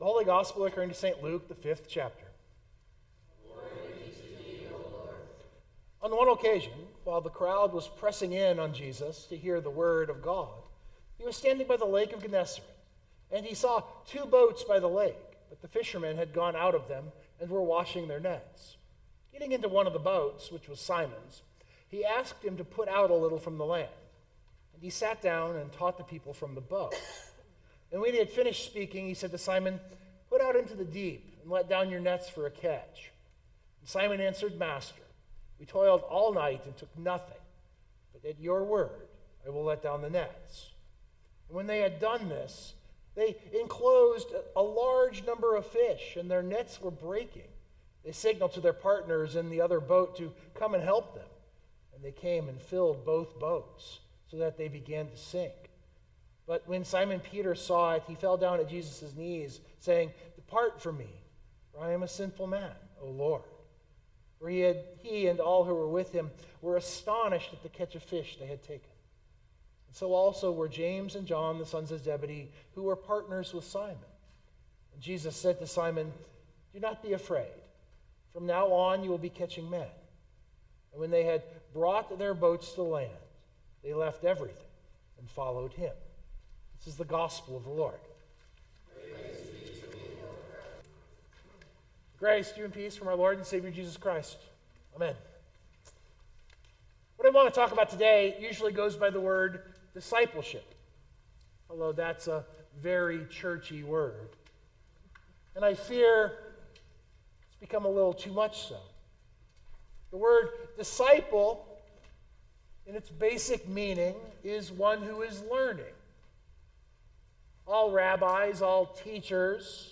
We in the church call this entire process discipleship. This sermon thinks about that